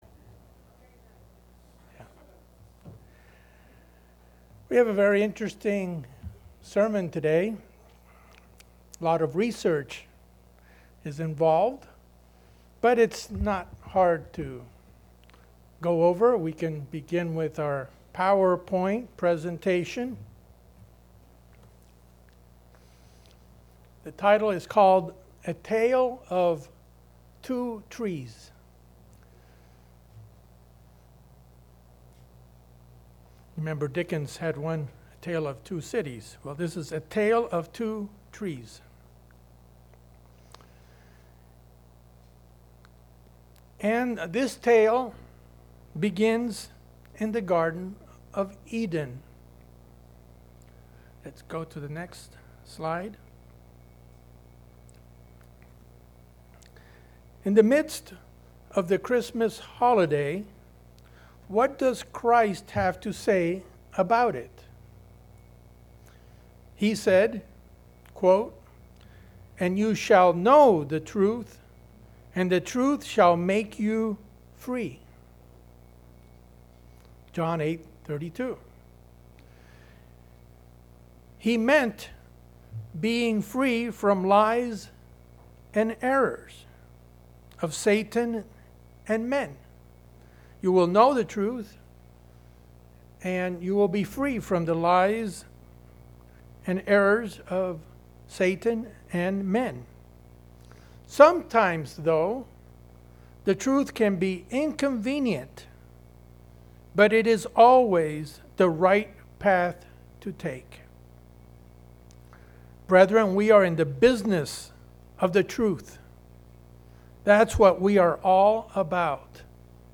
In this PowerPoint message, the history of Christmas and tree worship is examined. This activity is prophesied and explained using biblical scriptures.